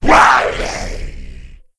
c_saurok_dead.wav